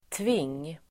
Ladda ner uttalet
Folkets service: tving tving substantiv, clamp Uttal: [tving:] Böjningar: tvingen, tvingar Definition: verktyg som håller fast arbetsstycken intill varandra Sammansättningar: skruvtving (screw clamp)